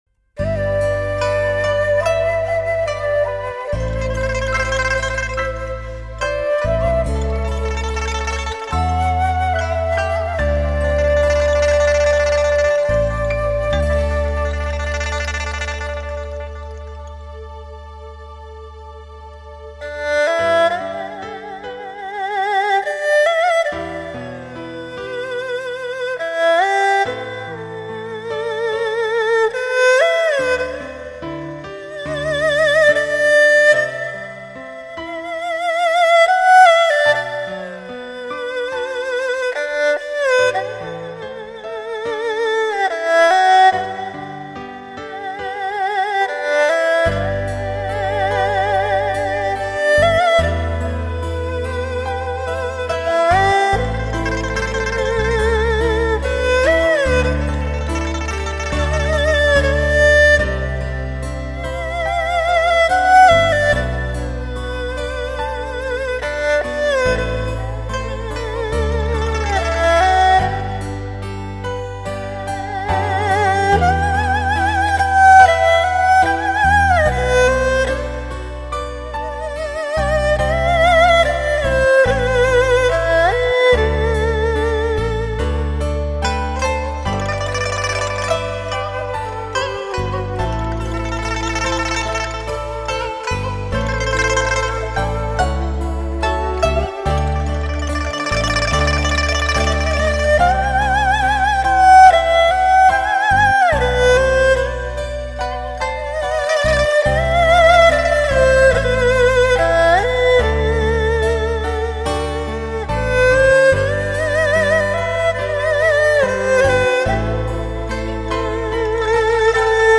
二胡演奏
德国母带处理
二胡幽怨凄美的阵阵炫动之下 段段旧日情怀